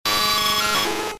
Cri de Nosferapti K.O. dans Pokémon Diamant et Perle.